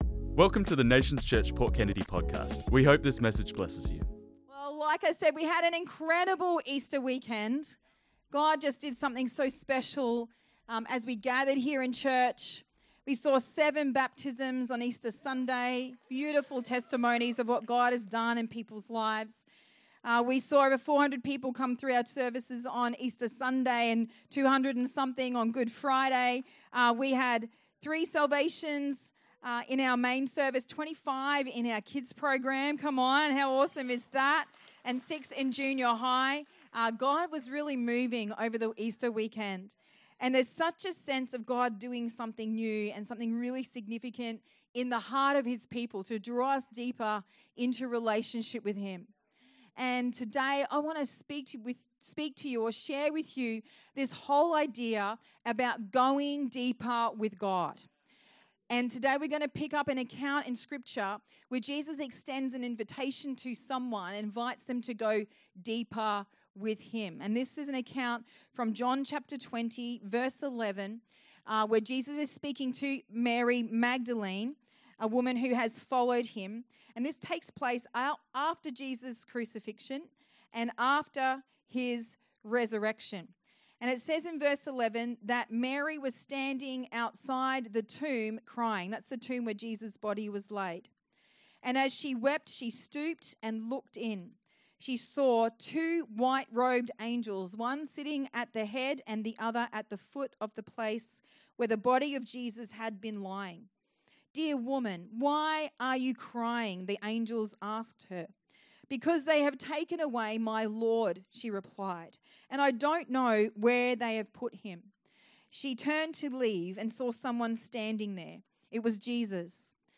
This message was preached on Sunday 27th April 2025